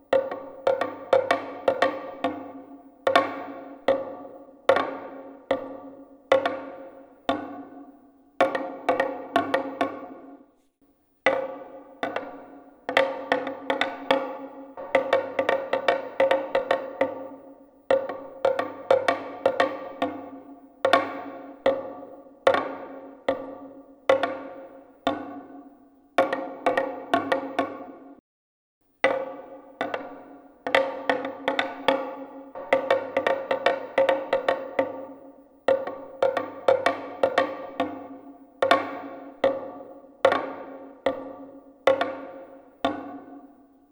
Instrumento musical idiófono golpeado directamente compuesto por tres piezas. Su diseño funcional está basado en la Txalaparta, La Tobera y Kirikoketa, especialmente esta última que se compone de tres piezas y poseen formas troncocónicas.